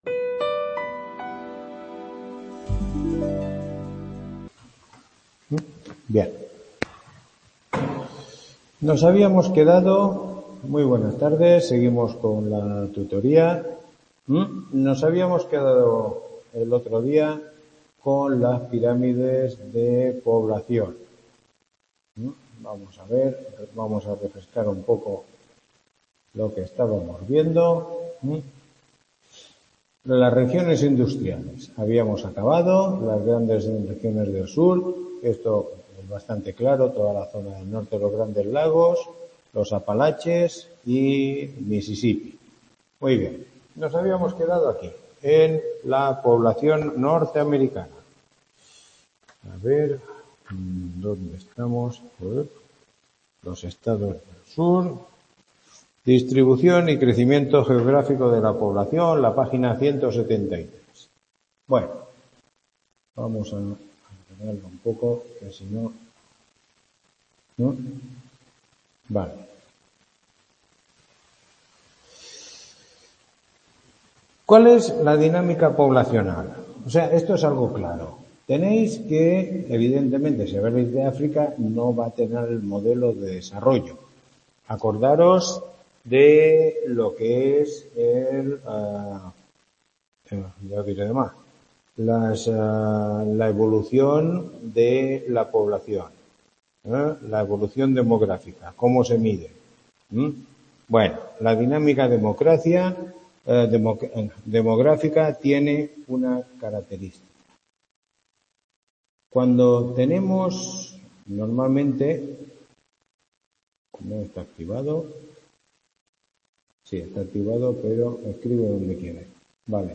Tutoría 07